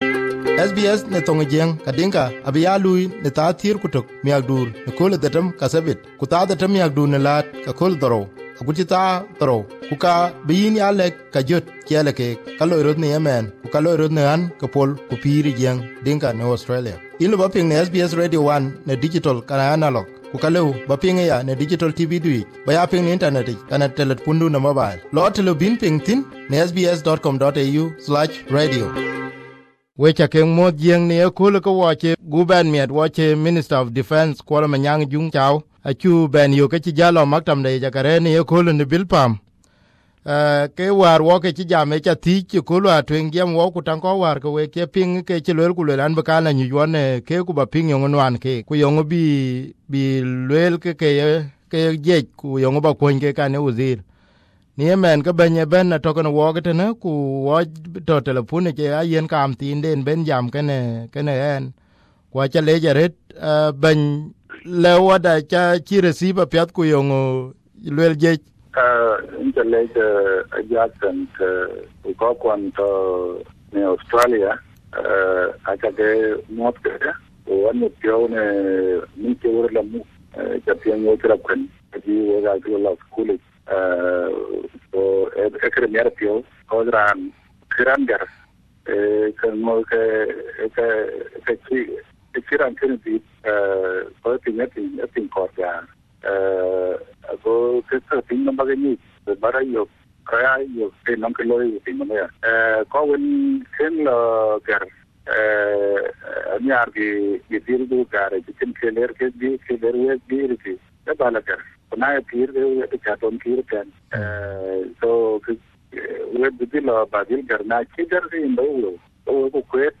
This is the first interview since he was appointed as Minister last week.